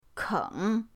keng3.mp3